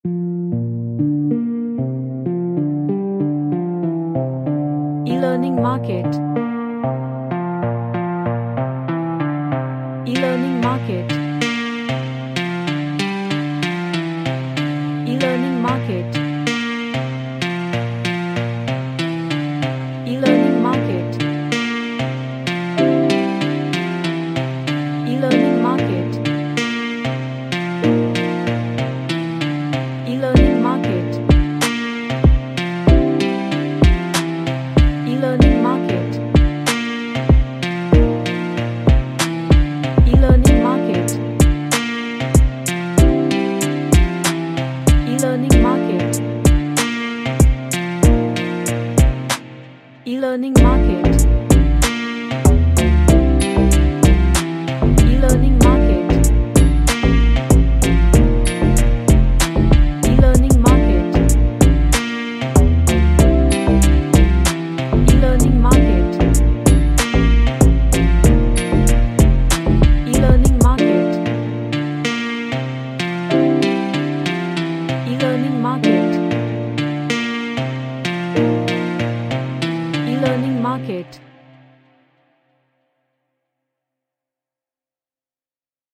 A retro type R&B track
Chill Out